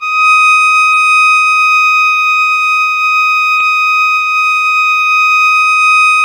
Index of /90_sSampleCDs/Roland L-CD702/VOL-1/STR_Violin 4 nv/STR_Vln4 no vib